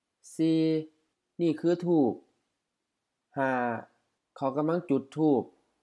ทูบ thu:p HF ธูป incense stick